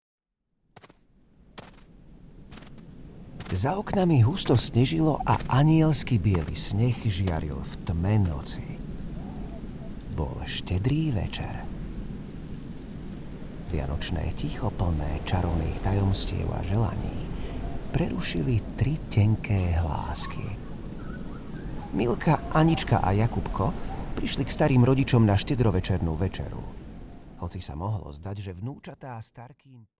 Vianočná pieseň audiokniha
Ukázka z knihy